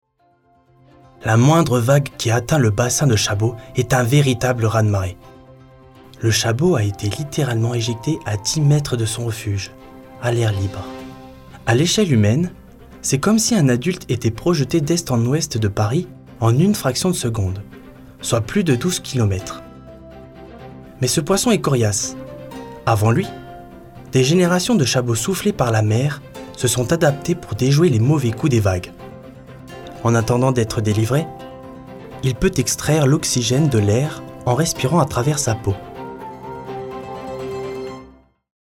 Documentaire
17 - 30 ans - Baryton